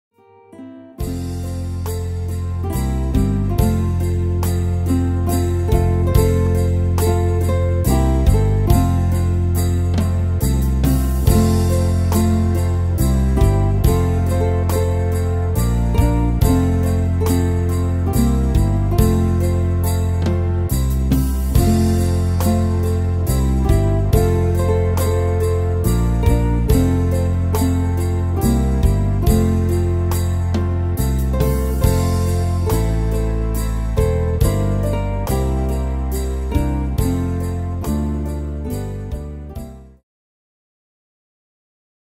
Tempo: 70 / Tonart: F-Dur